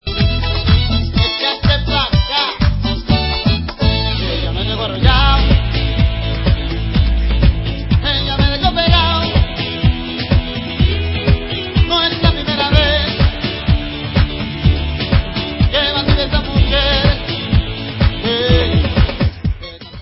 sledovat novinky v oddělení World/Latin